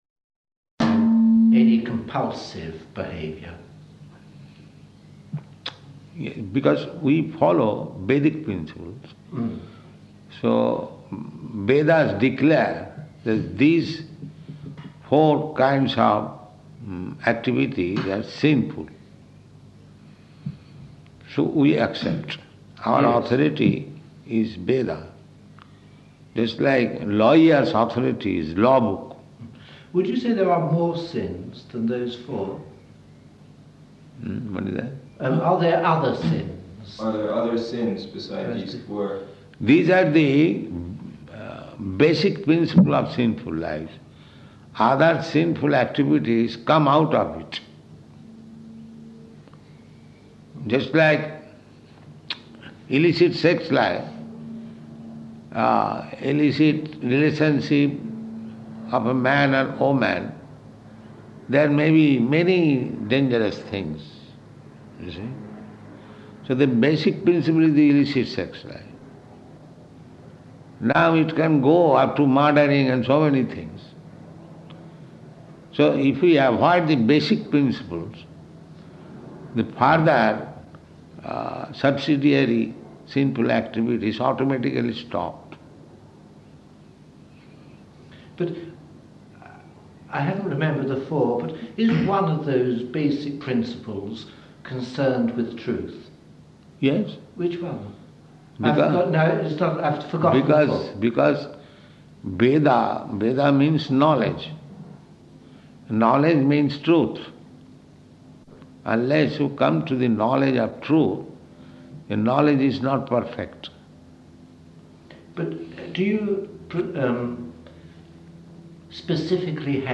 -- Type: Conversation Dated: July 11th 1973 Location: London Audio file